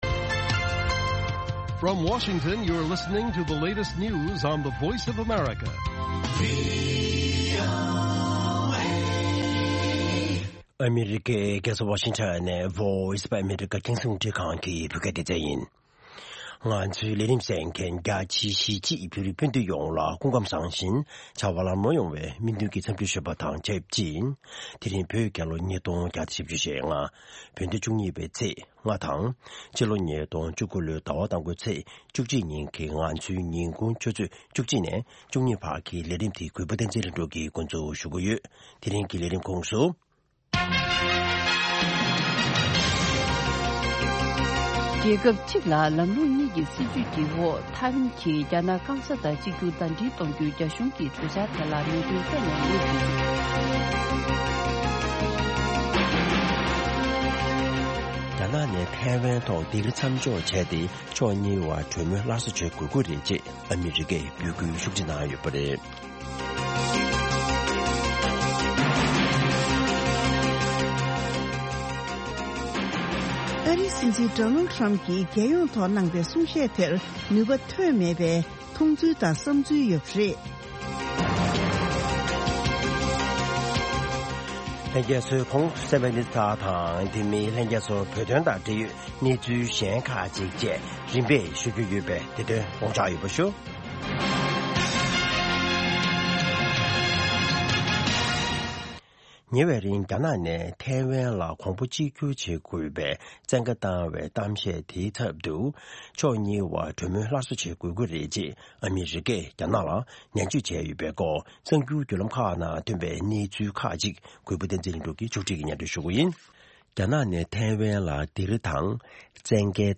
ཉིན་ལྟར་ཐོན་བཞིན་པའི་བོད་མའི་གནད་དོན་གསར་འགྱུར་ཁག་རྒྱང་སྲིང་ཞུས་པ་ཕུད། དེ་མིན་དམིགས་བསལ་ལེ་ཚན་ཁག་ཅིག་རྒྱང་སྲིང་ཞུ་བཞིན་ཡོད།